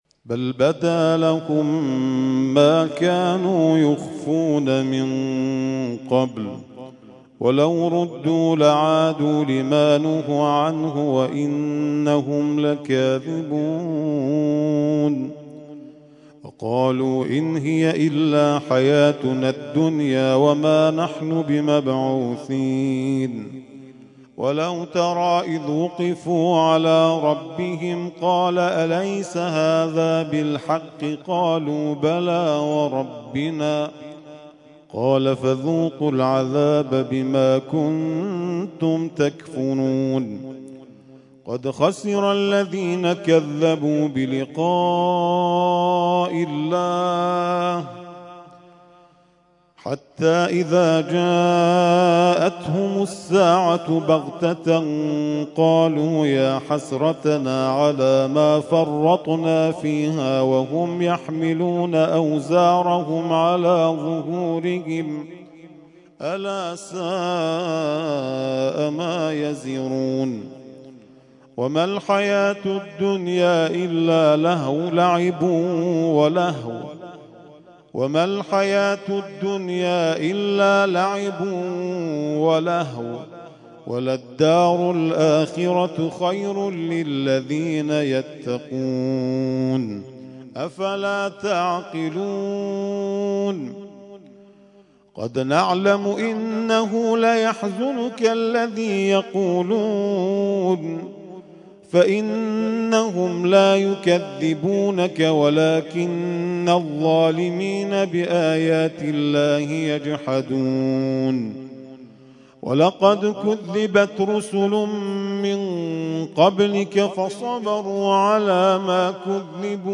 ترتیل خوانی جزء ۷ قرآن کریم در سال ۱۳۹۲